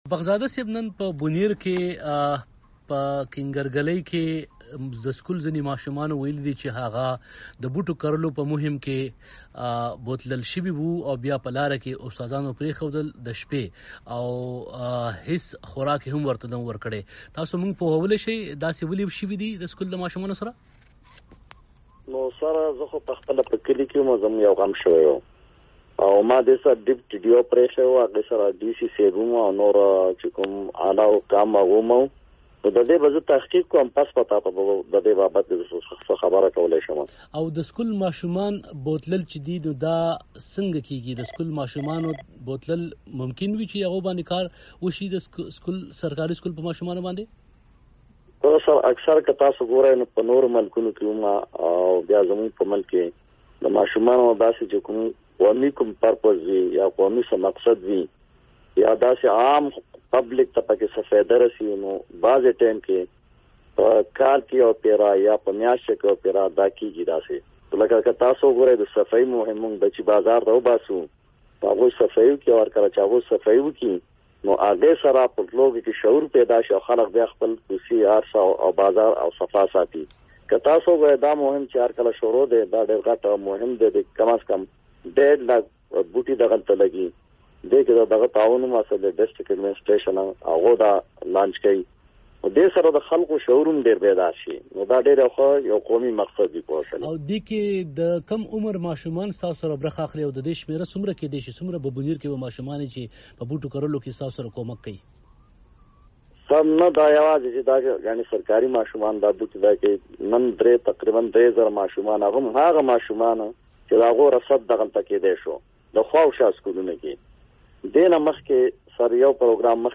د ښاغلي بخت ذاده مرکه